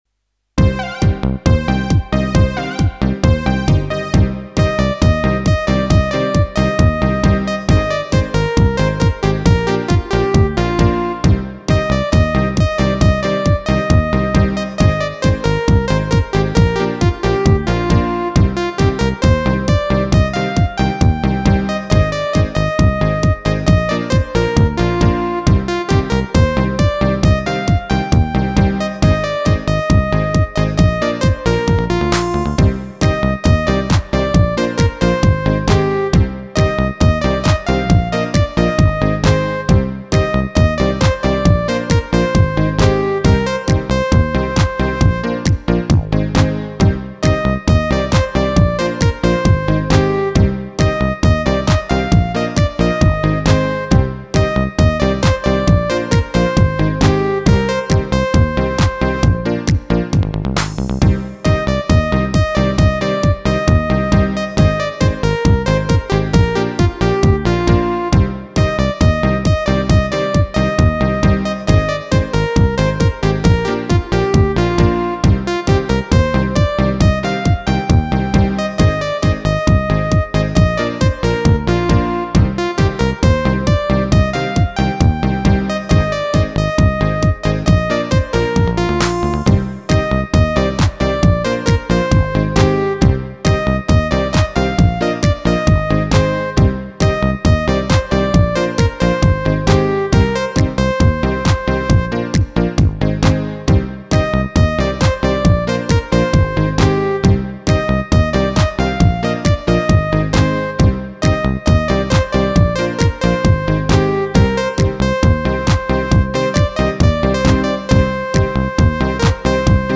8 Beat
Roland E X 10 8 Beat 003 Bad Beat Mp 3